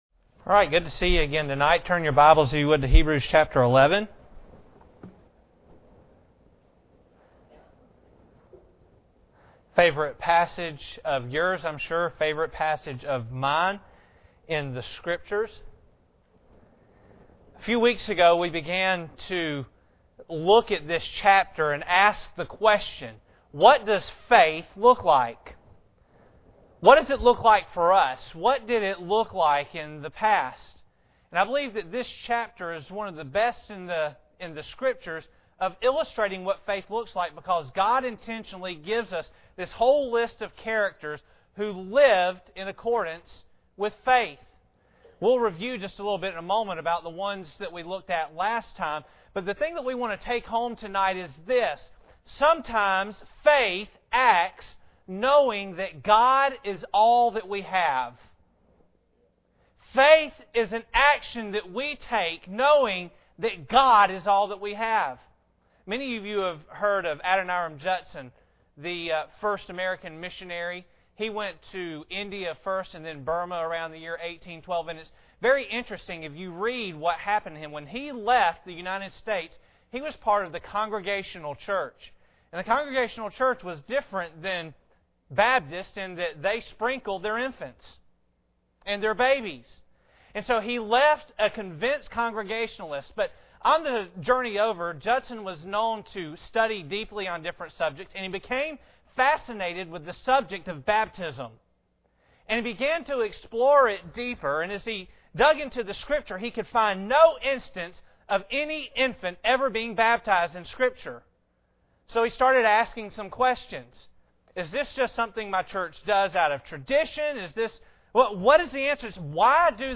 **Due to a technical glitch, the last few minutes of the sermon failed to record**